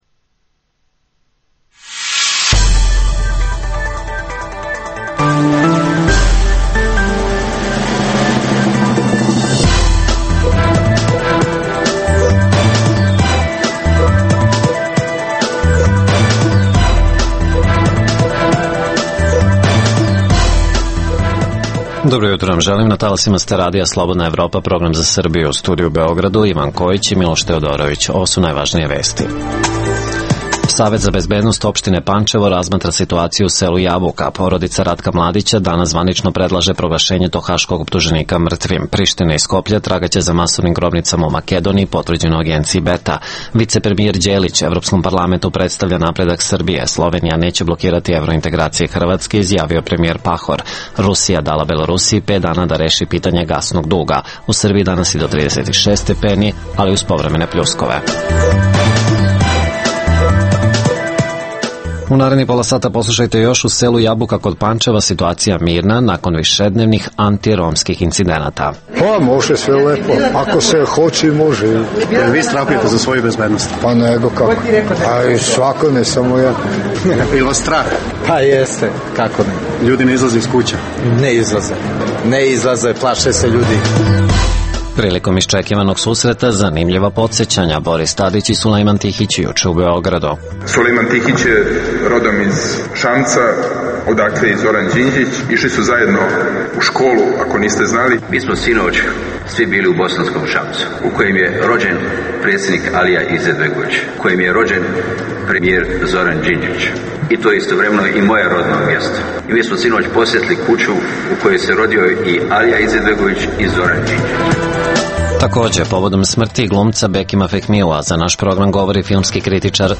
Savet za bezbednost opštine Pančevo razmatra situaciju u selu Jabuka, nakon niza antiromskih incidenata. Uživo razgovaramo sa gradonačelnicom Pančeva Vesnom Martinović.